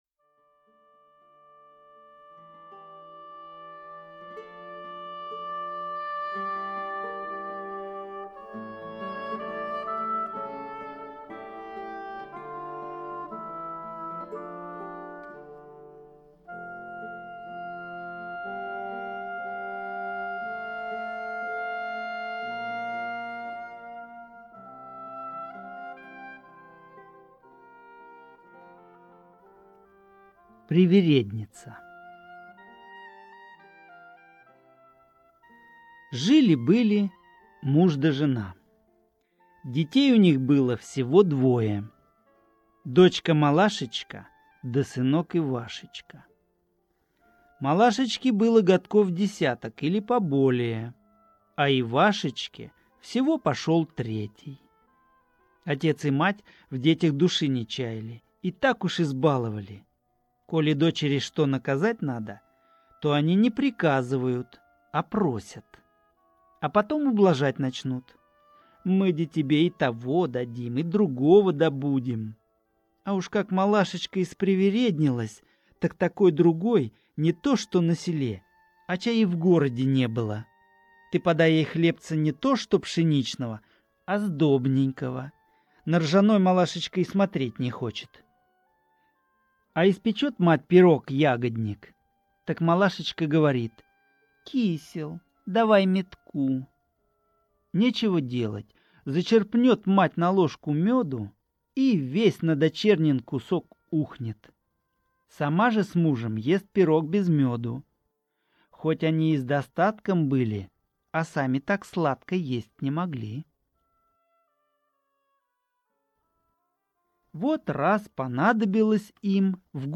Привередница - русская народная аудиосказка - слушать онлайн